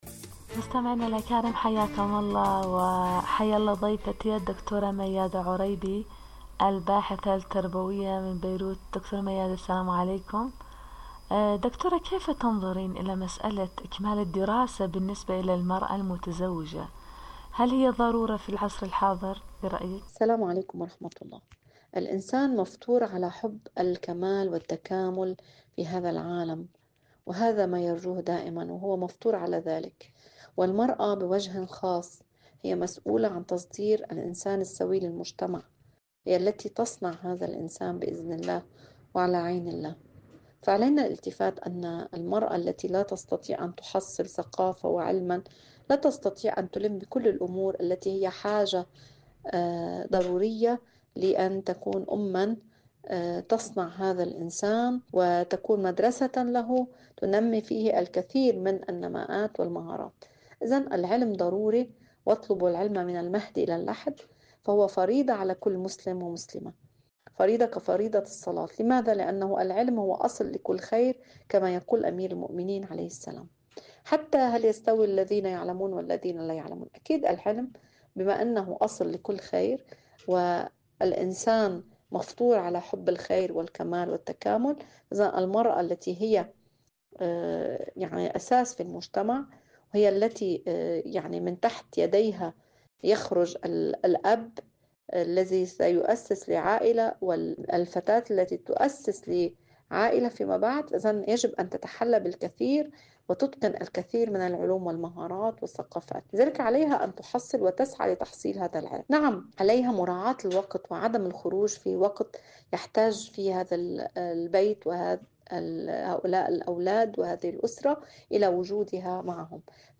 المرأة المتزوجة وإشكاليات إكمال الدراسة.. مقابلة